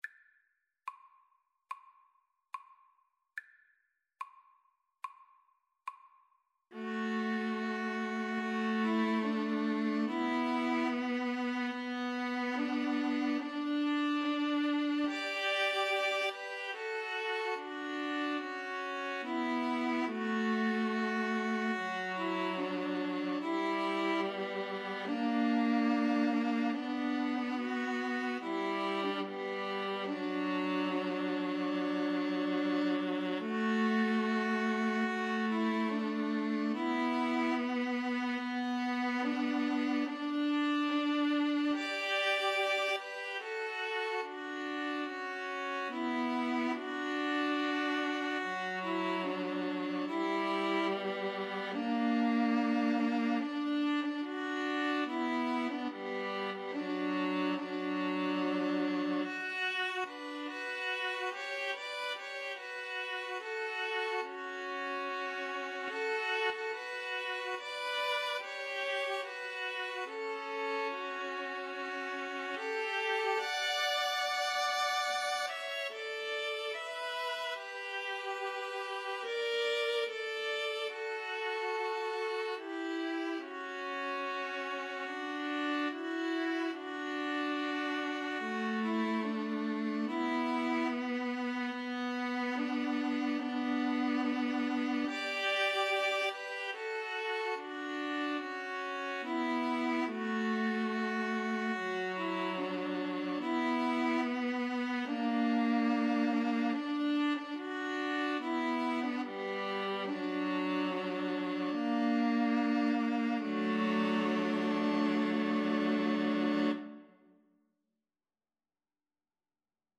ViolinViolaCello
4/4 (View more 4/4 Music)
D major (Sounding Pitch) (View more D major Music for String trio )
Andante =c.72
String trio  (View more Easy String trio Music)
Traditional (View more Traditional String trio Music)